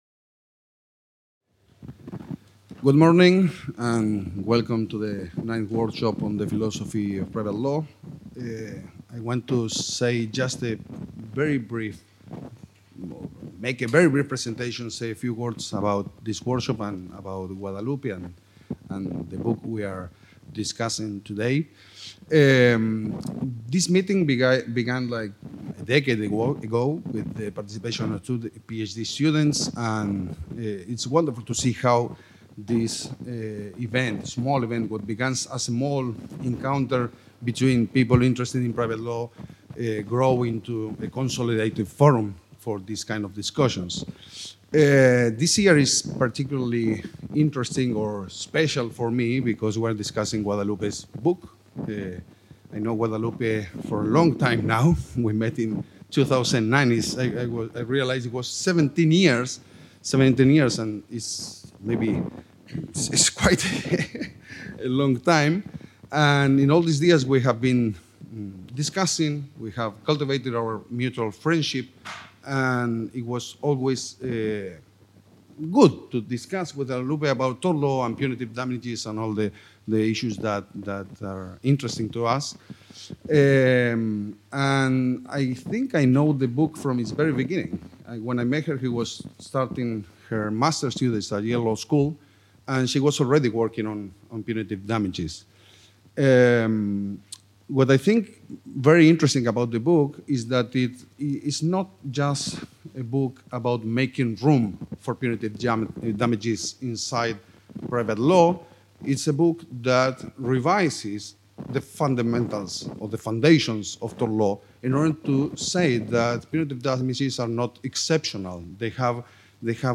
Presentació del Workshop. IX Philosophy of Private Law Workshop: Torts & Retribution (CUP, 2025)